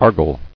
[ar·gal]